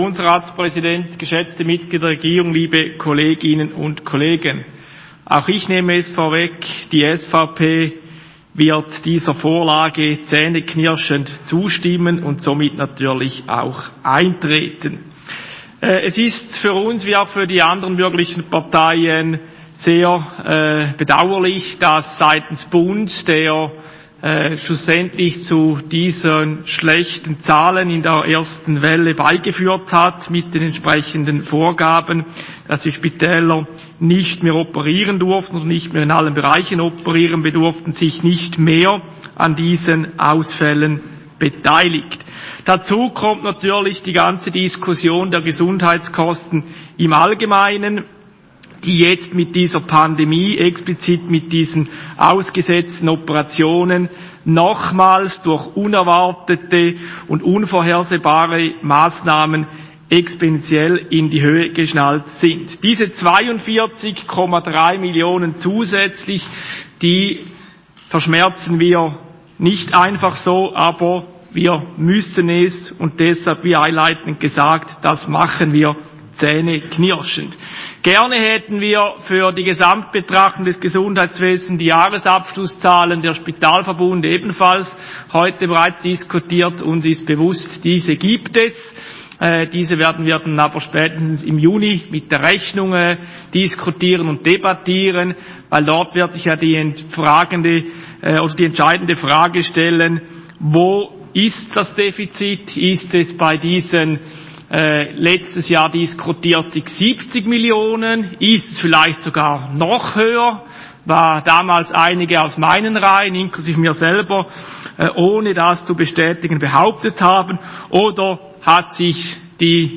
Session des Kantonsrates vom 15. bis 17. Februar 2021